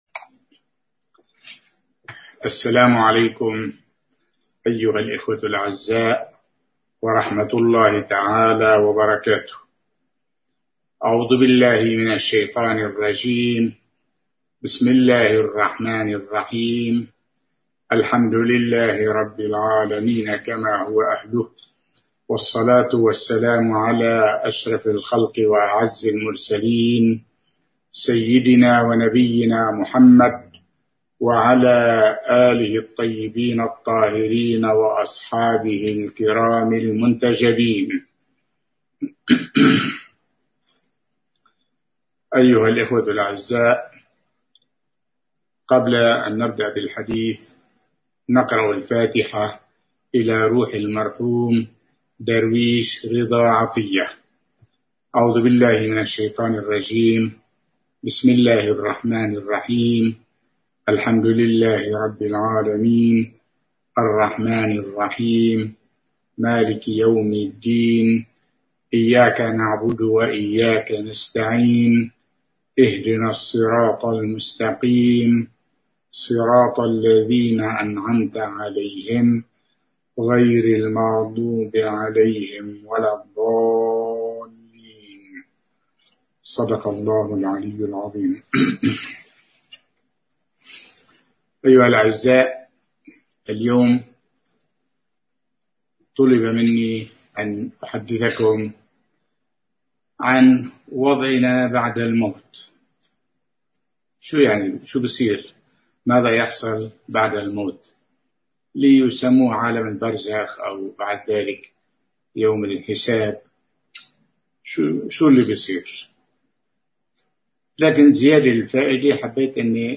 محاضرة
ألقاها في السنغال